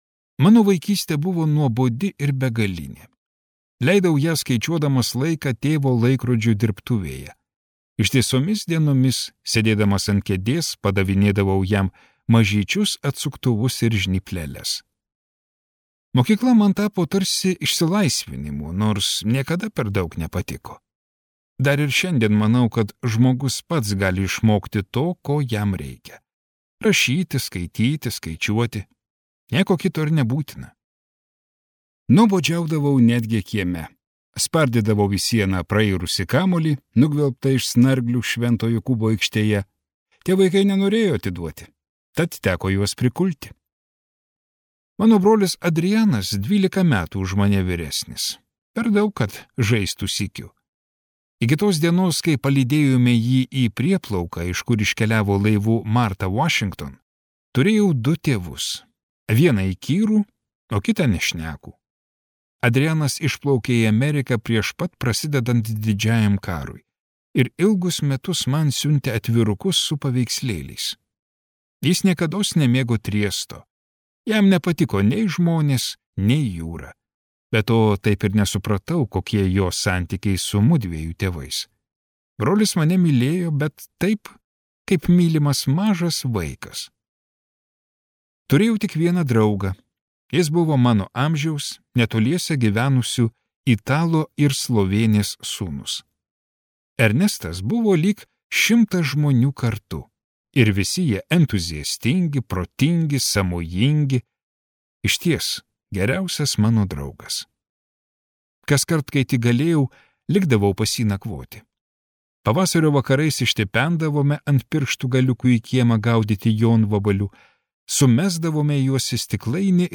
Marco Balzano audioknyga „Vaikas“ – tai istorija apie žiauriausią Triesto juodmarškinį, pravarde Vaikas.